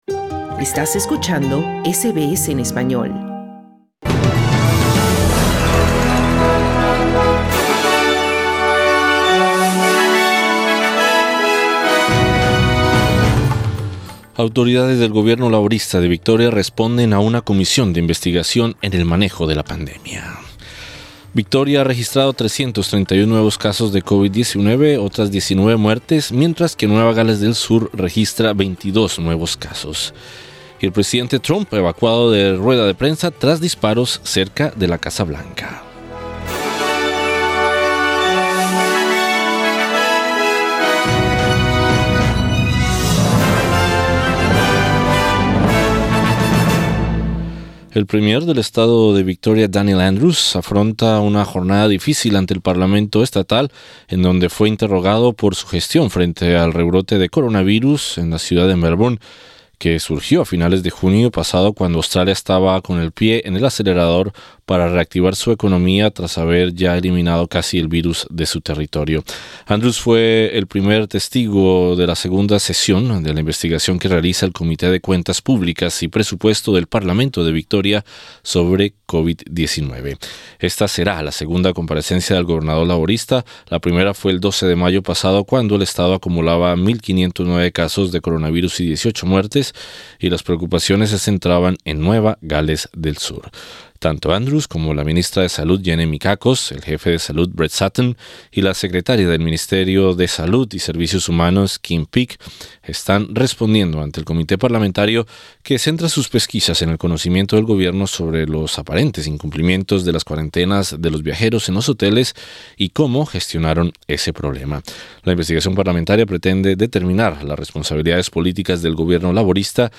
Noticias SBS Spanish | 11 de agosto 2020